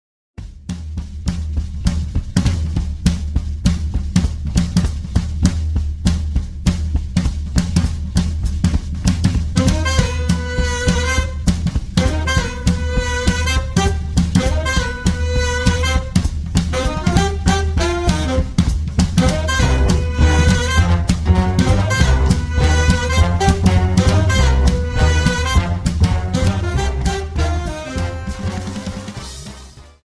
Dance: Quickstep Song
Quickstep 50